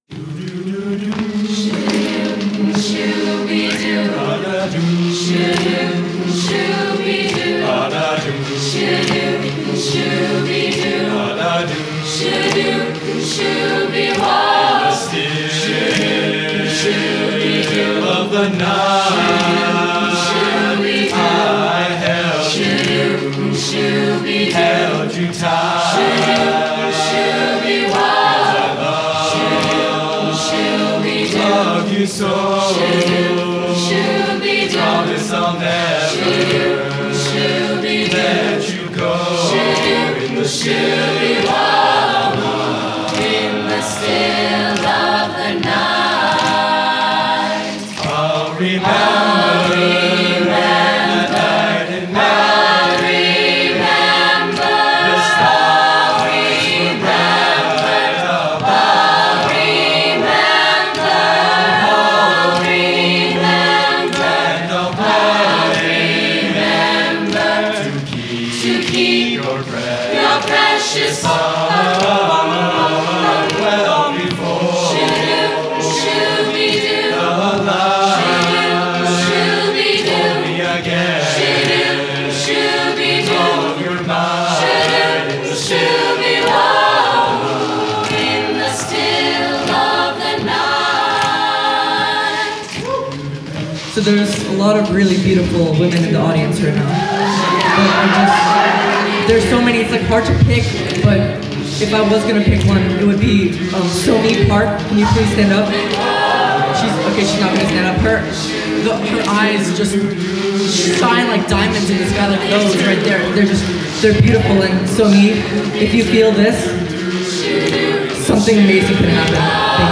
Spring Music Festival
05-A-Cappella-Choir-In-the-Still-of-the-Night.mp3